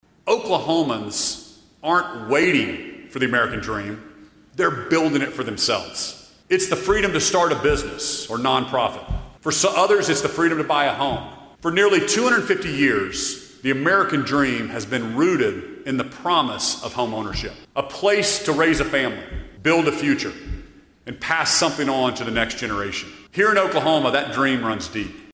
On Monday afternoon, Oklahoma Governor Kevin Stitt gave his final state of the state address.